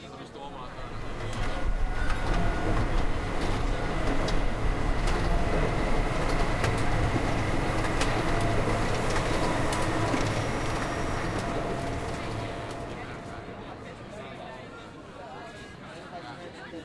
cityIndustrial.ogg